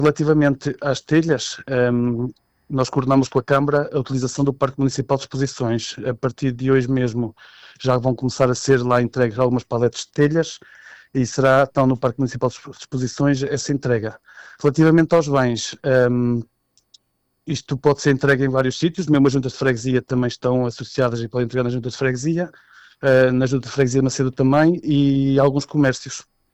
David Vaz deixa também informações importantes sobre os pontos de entrega e destaca a resposta positiva da comunidade local à campanha: